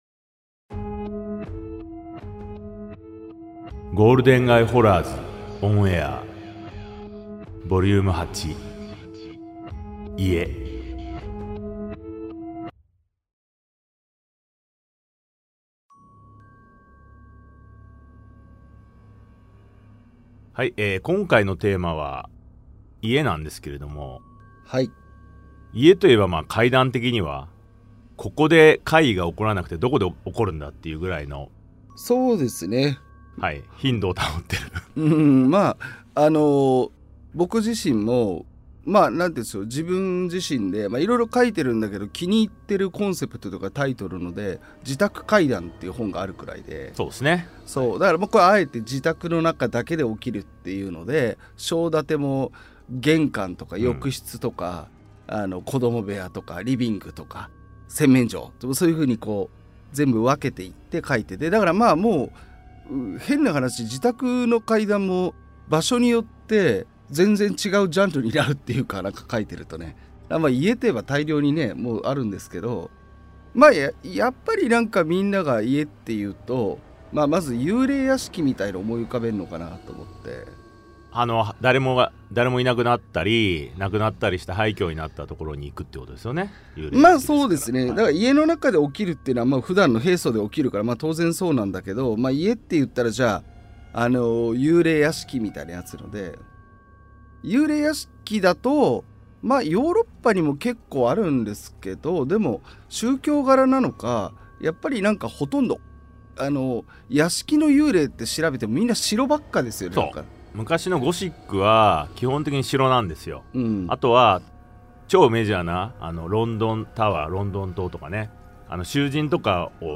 対談形式のホラー番組